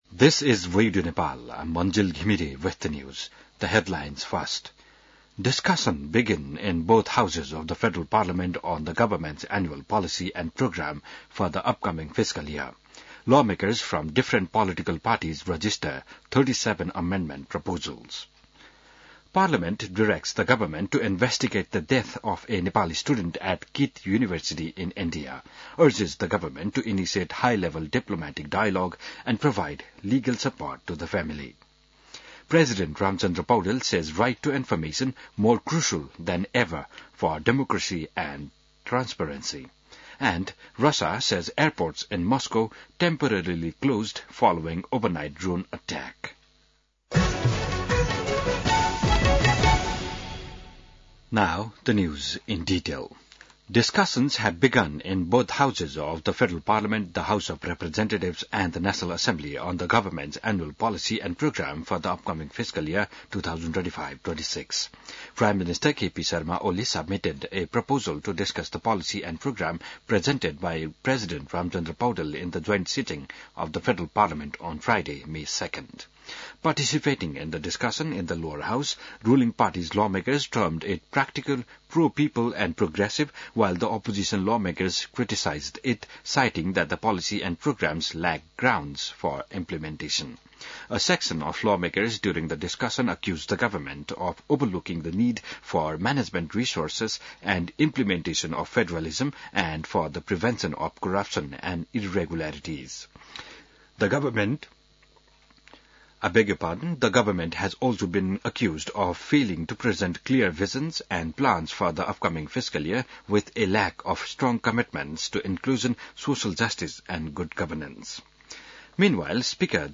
An online outlet of Nepal's national radio broadcaster
बिहान ८ बजेको अङ्ग्रेजी समाचार : २३ वैशाख , २०८२